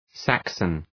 Προφορά
{‘sæksən}